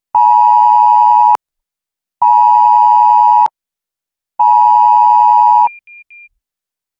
Alert-systems Sound Effects - Free AI Generator & Downloads
tone-of-a-alert-system-zbep3p3n.wav